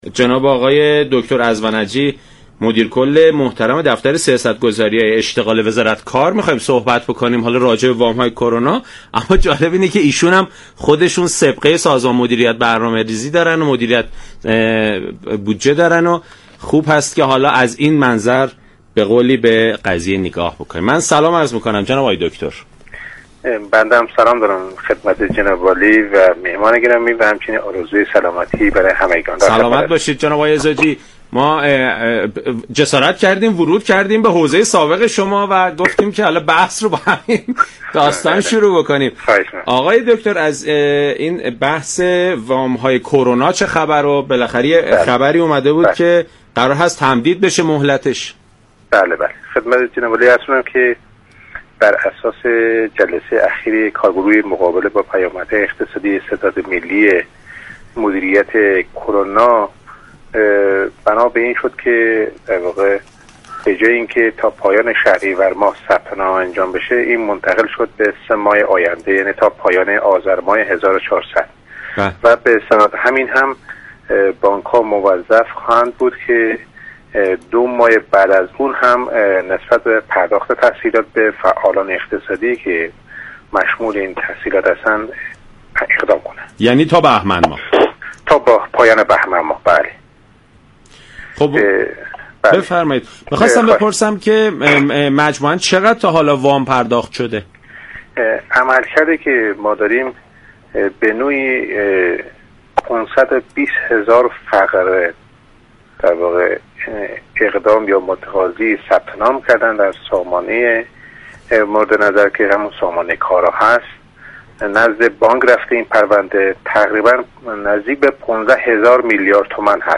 به گزارش شبكه رادیویی ایران، علاءالدین ازوجی مدیر كل سیاتهای اشتغال وزارت تعاون،‌ كار و رفاه اجتماعی در برنامه «سلام صبح بخیر» رادیو ایران درباره مهلت ثبت نام وام كرونا خبر داد و گفت: بر اساس اطلاعیه و مصوبه كارگروه مقابله با پیامدهای اقتصادی ناشی از شیوع كرونا، مهلت پرداخت وام جدید كرونا تا پایان آذرماه تمدید شد، به استناد این مصوبه بانك های كشور تا پایان بهمن سال جاری موظف به ارائه تسهیلات به فعالان اقتصادی هستند.